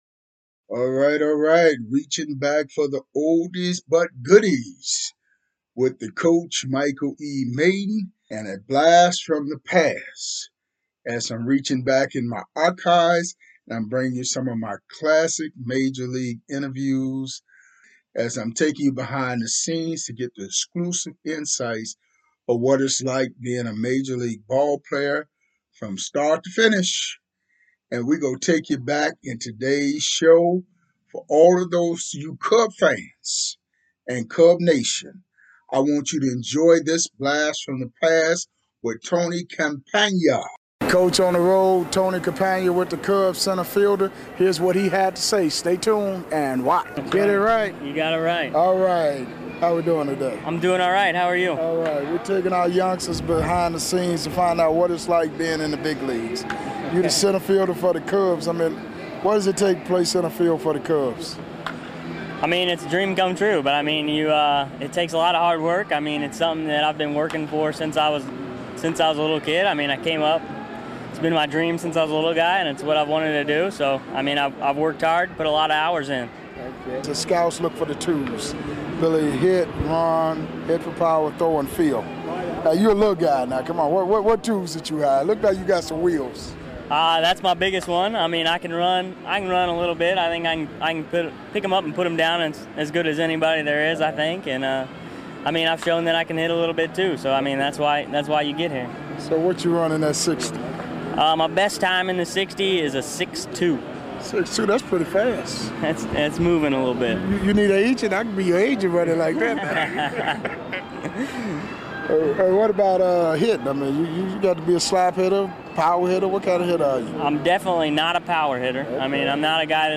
Classic MLB Interviews